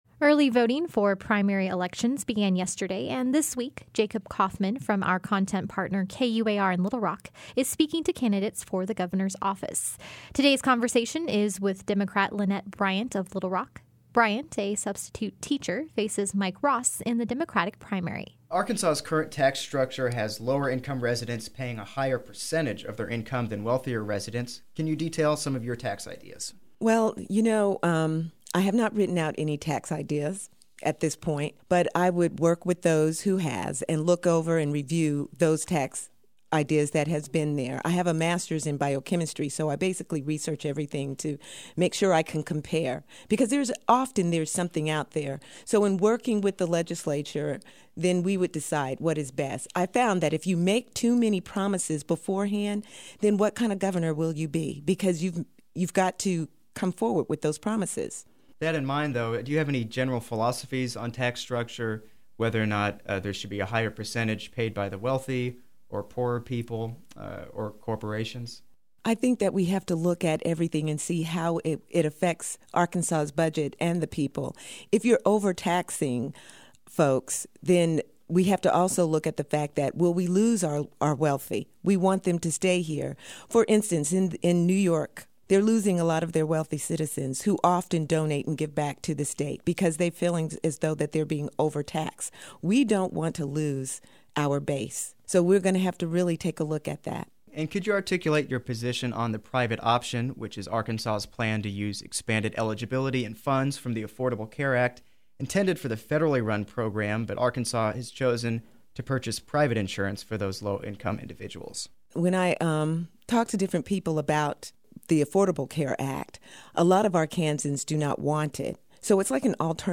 Our content partner KUAR in Little Rock is interviewing Arkansas' gubernatorial candidates.